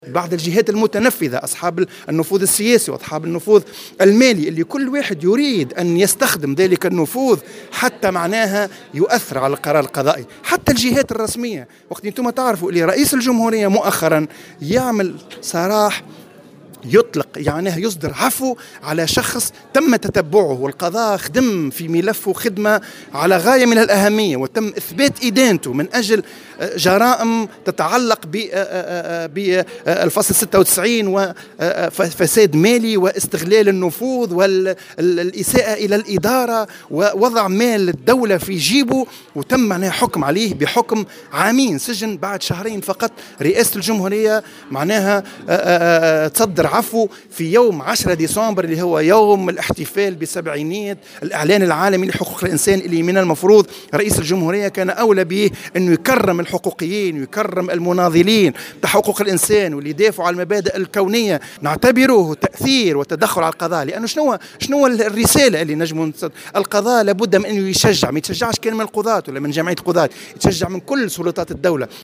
في تصريح لمراسلة الجوهرة اف ام